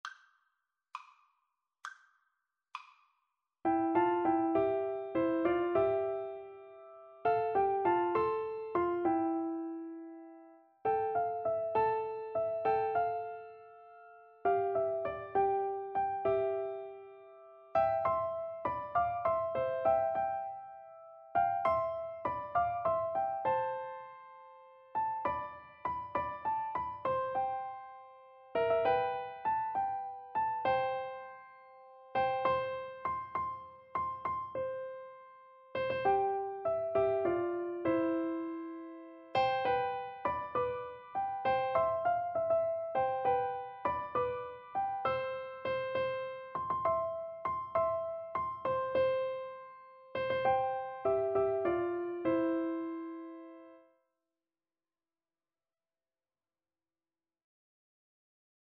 6/8 (View more 6/8 Music)
Piano Duet  (View more Intermediate Piano Duet Music)
Classical (View more Classical Piano Duet Music)